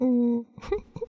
女生害羞的笑声音效免费音频素材下载